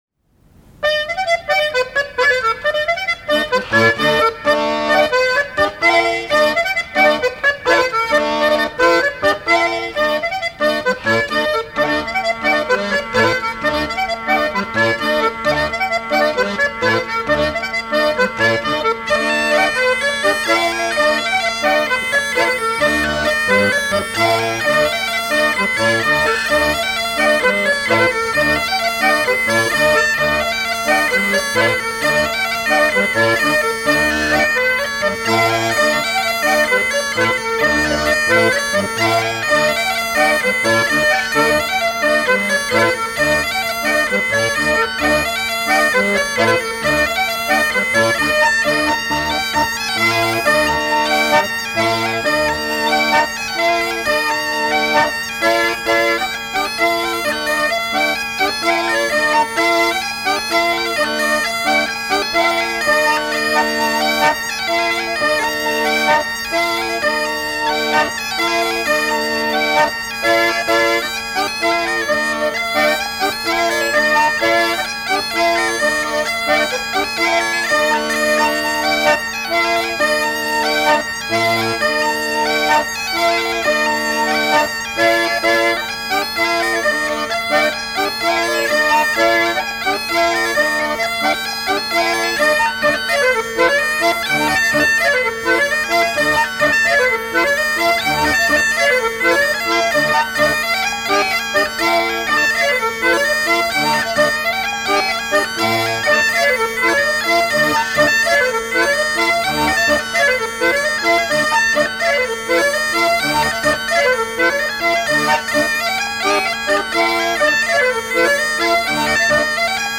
Mémoires et Patrimoines vivants - RaddO est une base de données d'archives iconographiques et sonores.
danse : branle : courante, maraîchine
Pièce musicale inédite